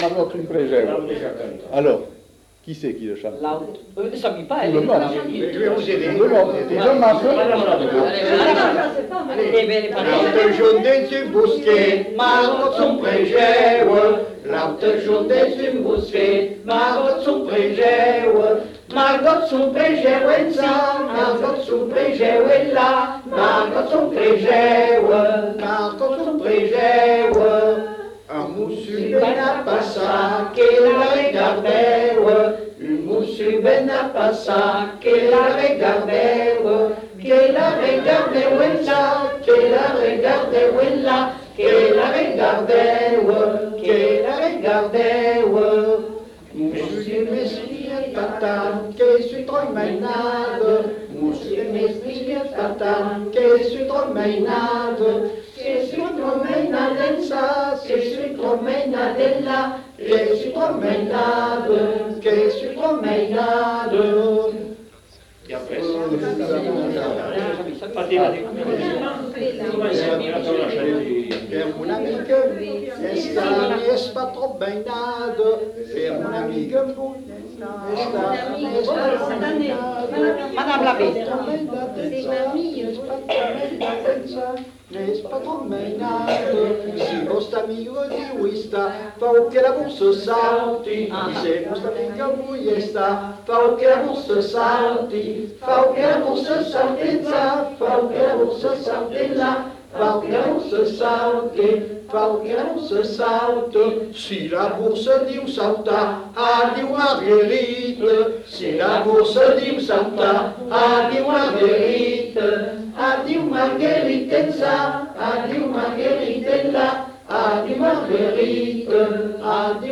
Lieu : Bazas
Genre : chant
Type de voix : voix mixtes
Production du son : chanté
Bribes de chants en fin de séquence.